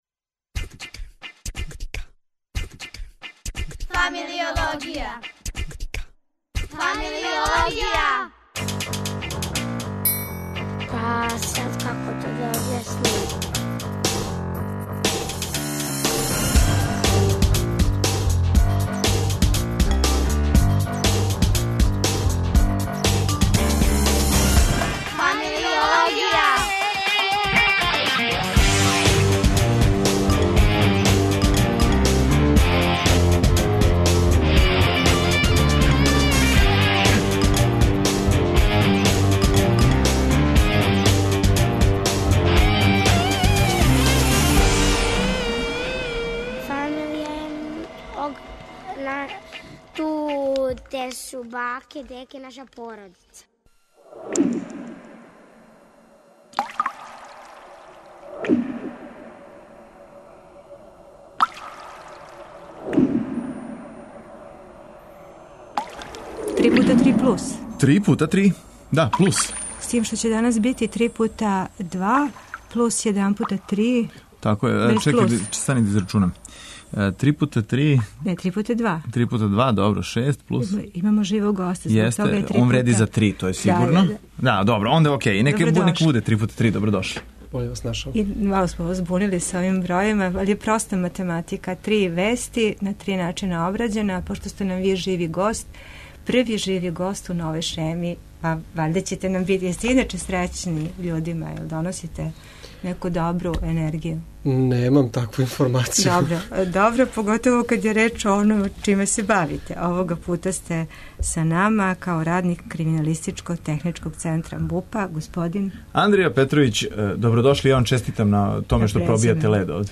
Јер, ако је гост у студију, онда је то плус? Говоримо о отисцима прстију, захвалности, мало срцу...